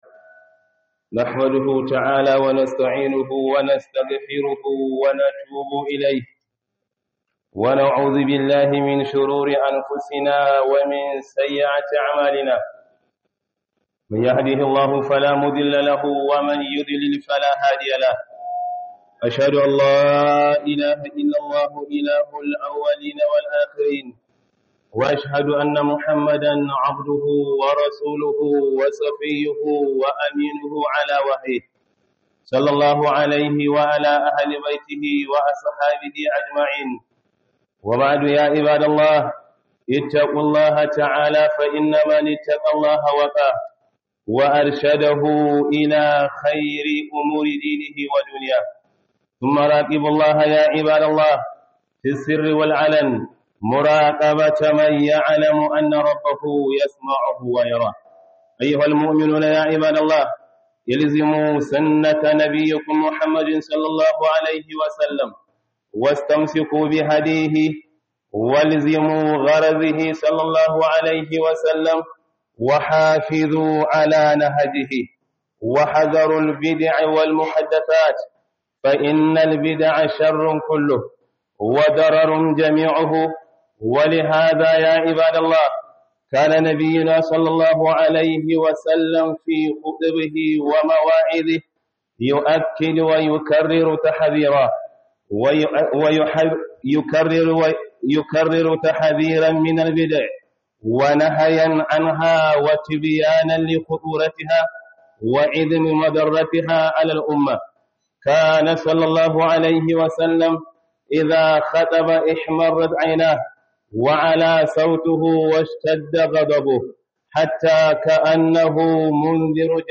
Khuduba